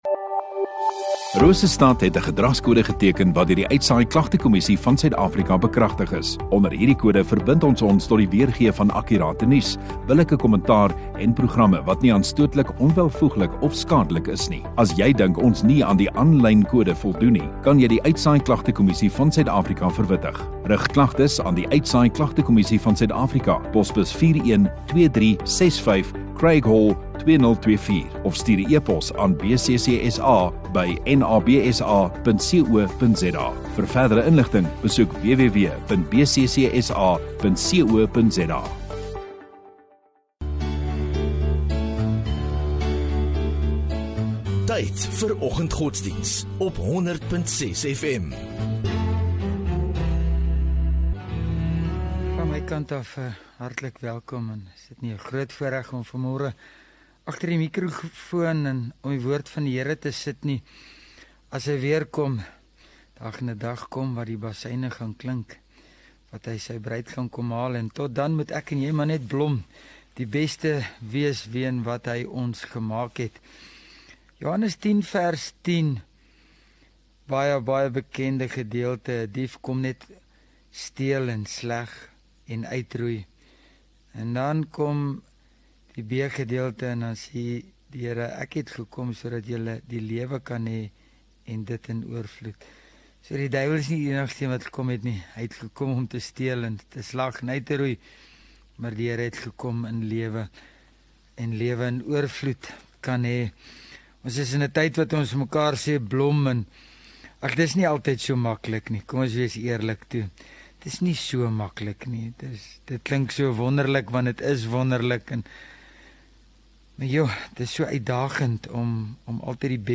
28 Aug Donderdag Oggenddiens